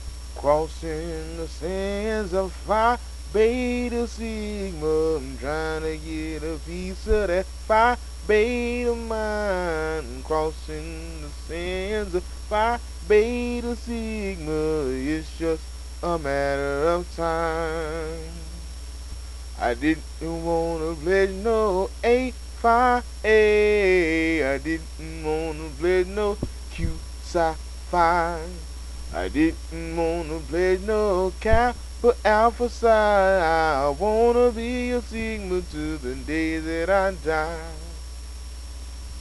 Sigma Chapter Chants